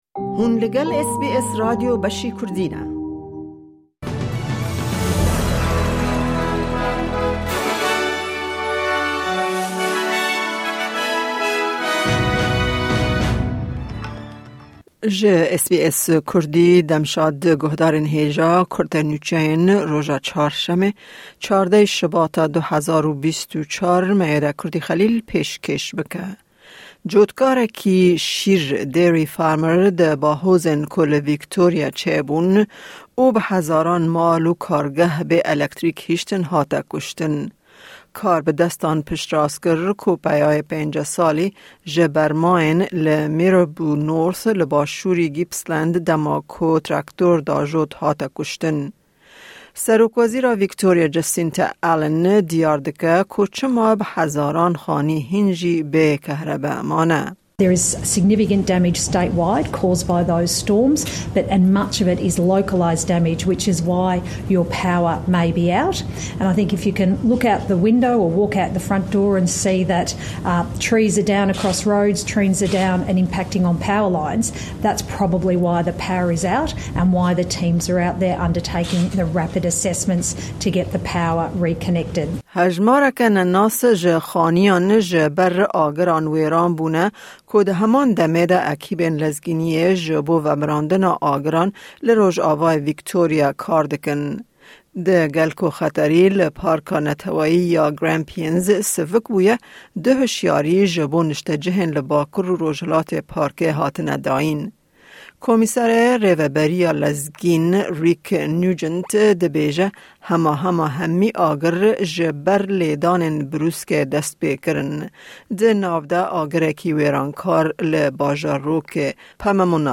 Kurte Nûçeyên roja Çarşemê 14î Şubata 2024